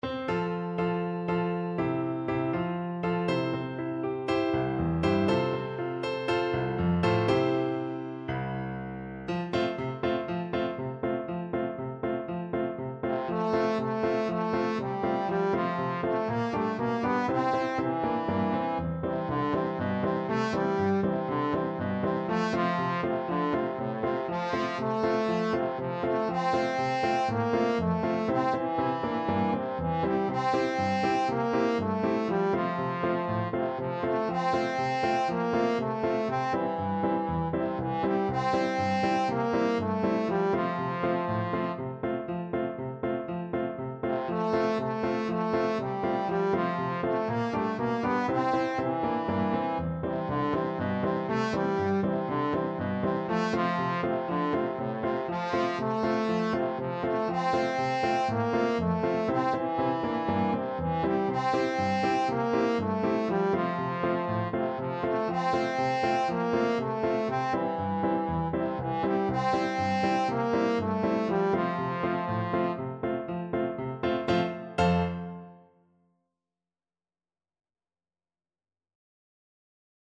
Trombone
F major (Sounding Pitch) (View more F major Music for Trombone )
Allegro (View more music marked Allegro)
2/4 (View more 2/4 Music)
Traditional (View more Traditional Trombone Music)
Mexican